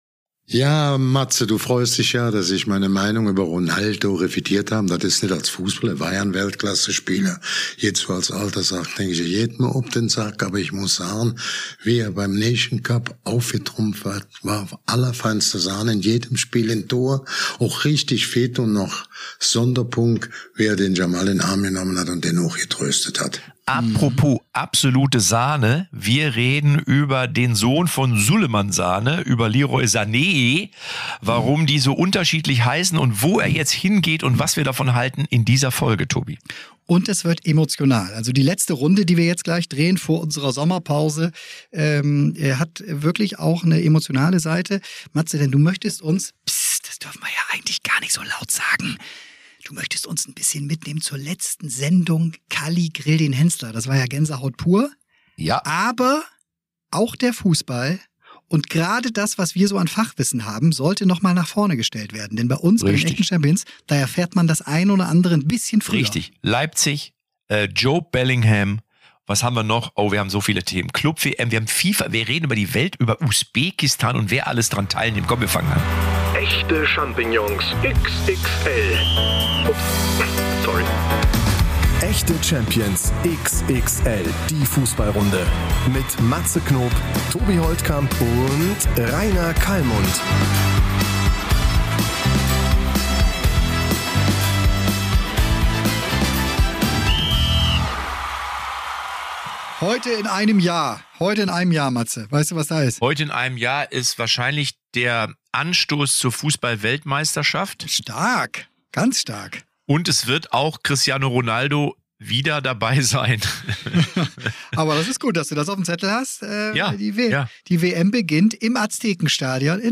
Live aus dem Stadion: Hochspannung wegen Kovac und Pep.